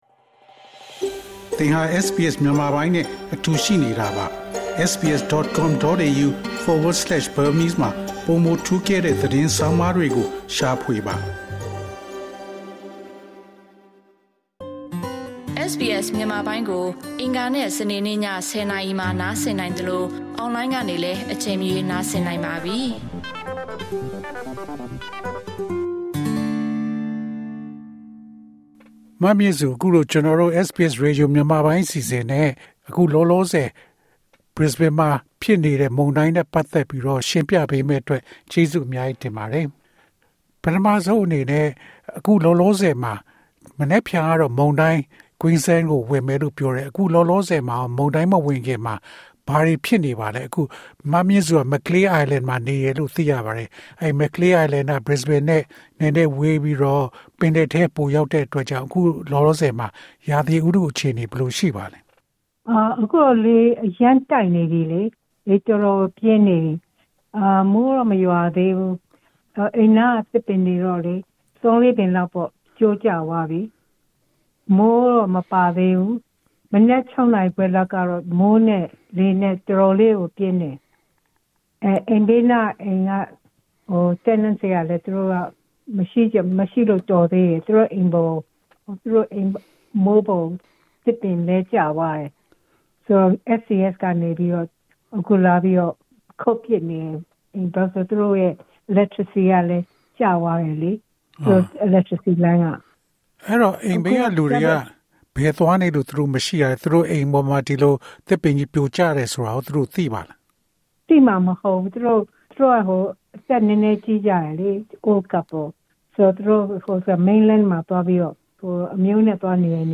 အင်တာဗျူး။